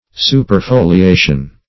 Superfoliation \Su`per*fo`li*a"tion\